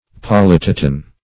Polyptoton \Pol`yp*to"ton\, n. [L., fr. Gr.